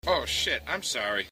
Featuring a punchy beat with iconic "oh", "shit", "iam", it's a staple in modern Game SFX sound and meme culture.
This sound effect is perfect for enhancing transitions, emphasizing punchlines, or adding rhythm to fast-paced edits.